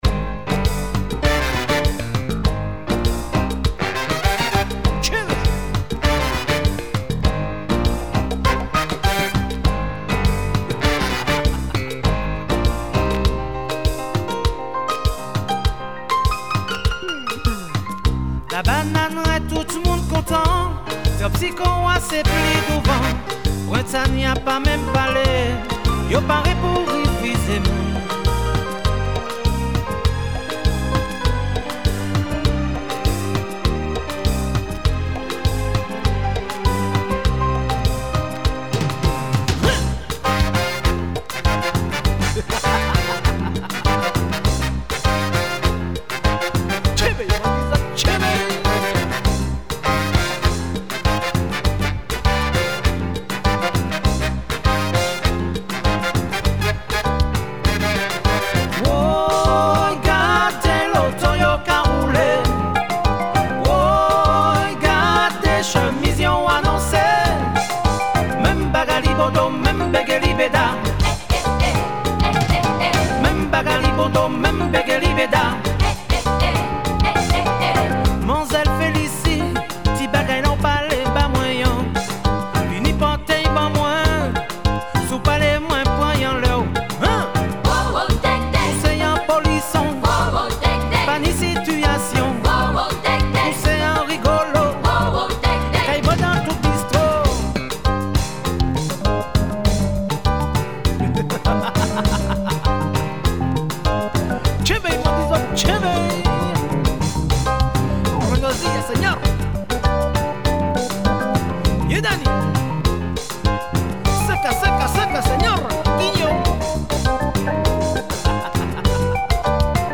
Killer zouk funk.
Caribbean